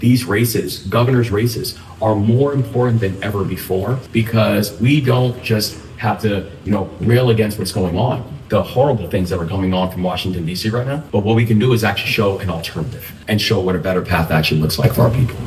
Moore spoke to voters about the growing importance of state governors right now…